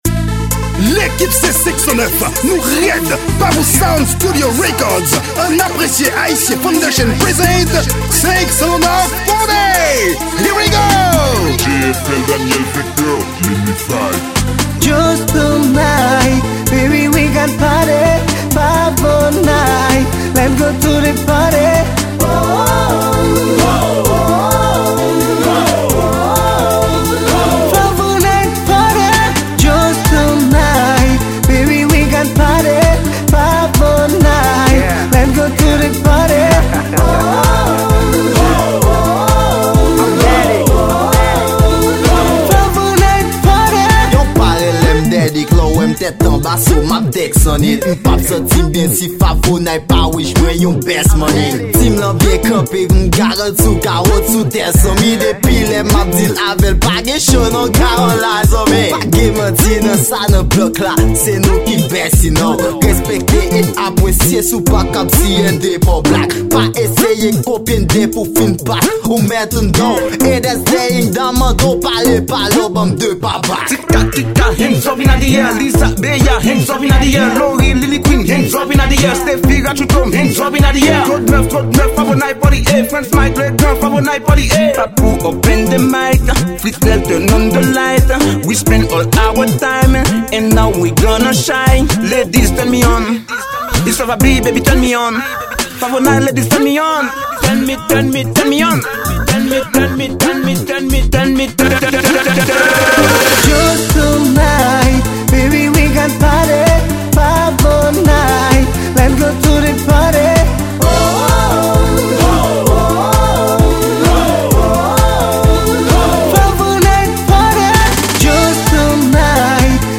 Genre: Techno.